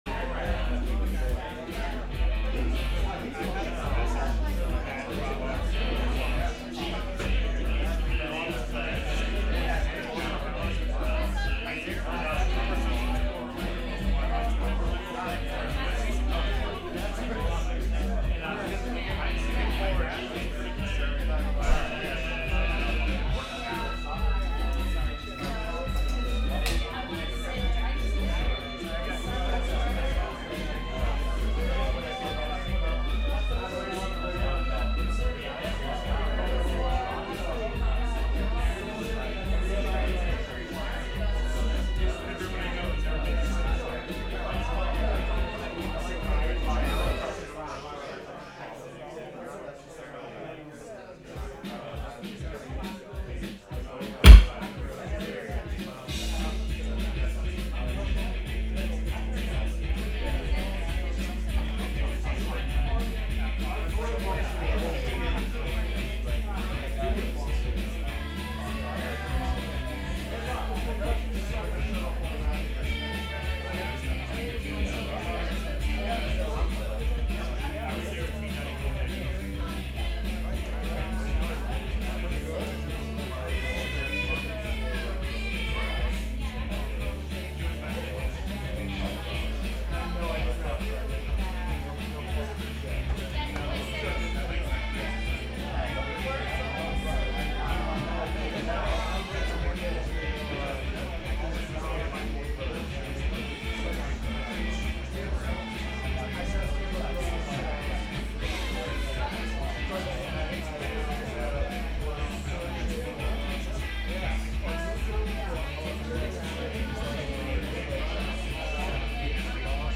Wave Farm Radio is pleased to stream Ende Tymes X: Festival of Noise and Experimental Liberation (April 4-7, 2019).